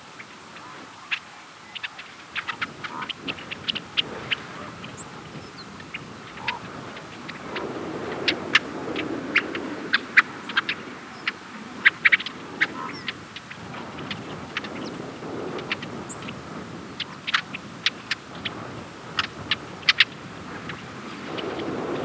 Yellow-headed Blackbird diurnal flight calls
"Quip" calls from birds in flight with Brewer's and Red-winged Blackbirds, White-crowned Sparrow, and Horned Lark.